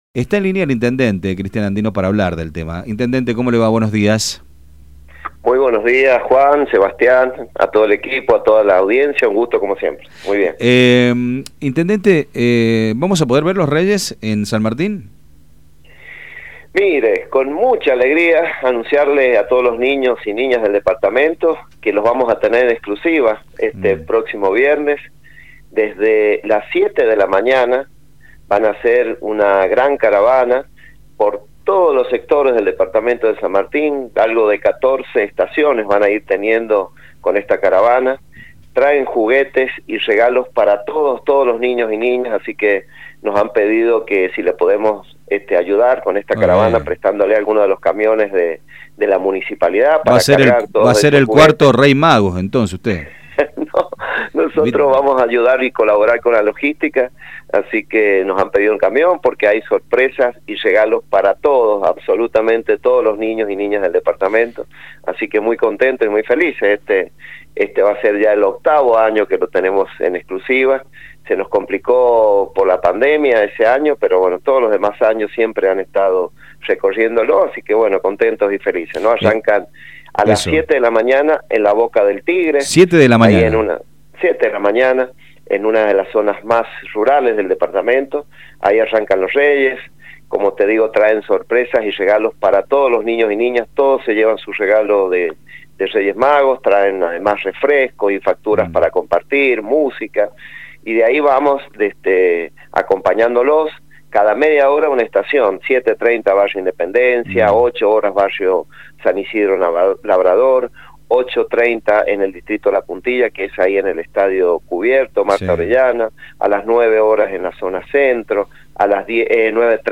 Por este motivo, el intendente de San Martín estuvo en los micrófonos de Radio Sarmiento para brindar detalles sobre los preparativos de la gran caravana que recorrerá el departamento.